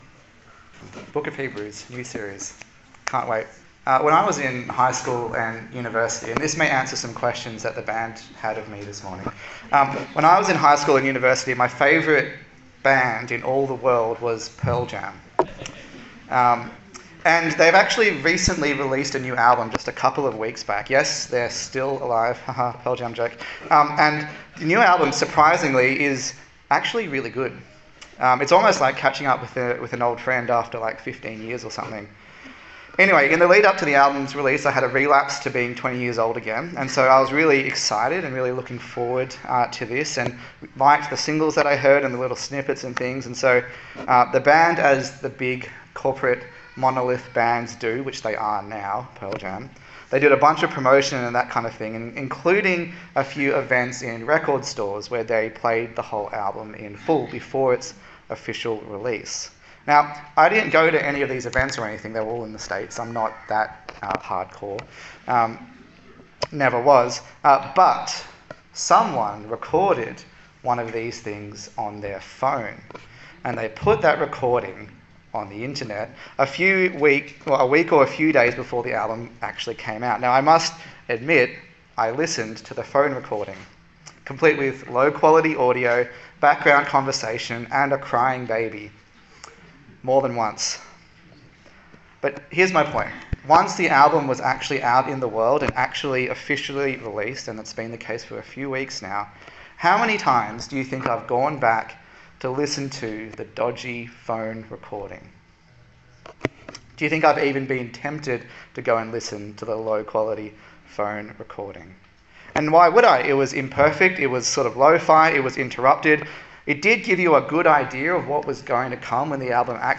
A sermon in the series on the letter to the Hebrews
Service Type: Sunday Service